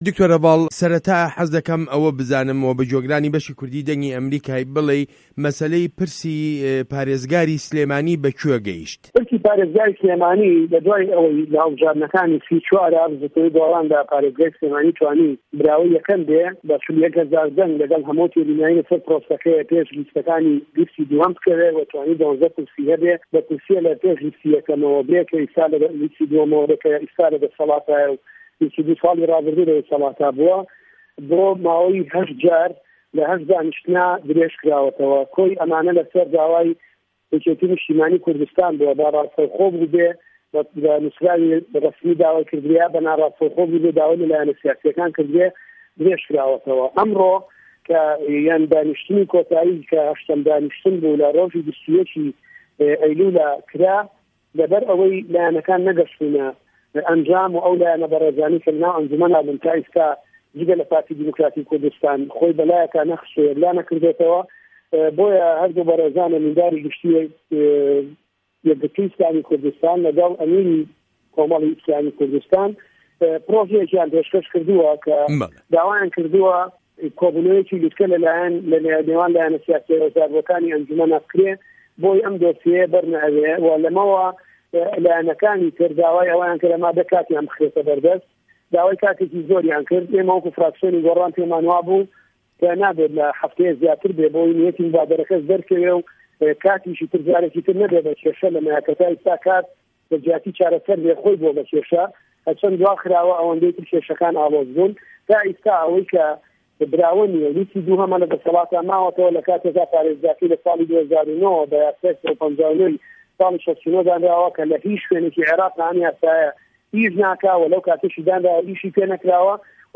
وتووێژ له‌گه‌ڵ دکتۆر هه‌ڤاڵ ئه‌بوبه‌کر